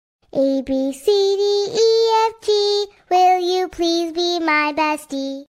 This kitten’s sweet tune about becoming your bestie will charm you from the first “meow”!